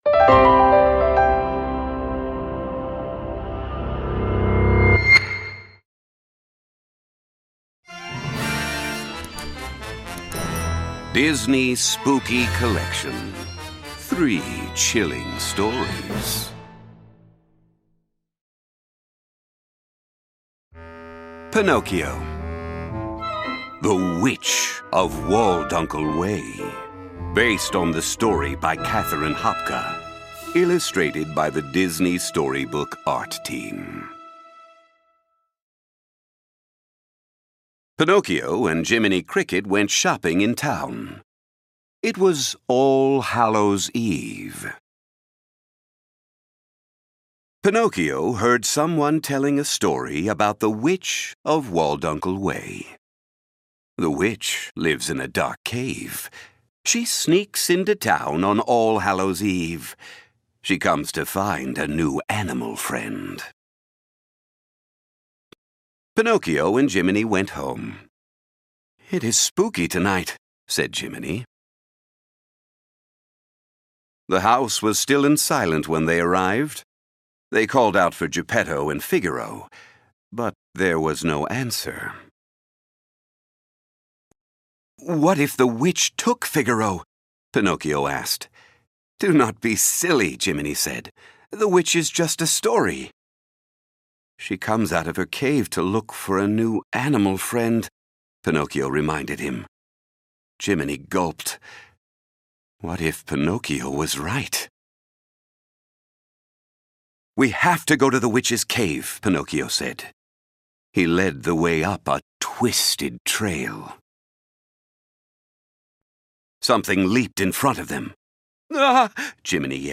Libros Narrados